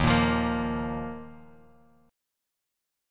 D#sus2 chord